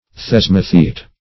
thesmothete - definition of thesmothete - synonyms, pronunciation, spelling from Free Dictionary
Search Result for " thesmothete" : The Collaborative International Dictionary of English v.0.48: Thesmothete \Thes"mo*thete\, n. [Gr.